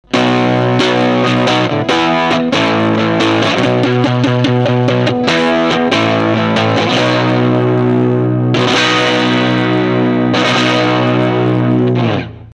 なるへそ、GEより音圧感が少なく感じられますが
出音自体のバランスは良いですし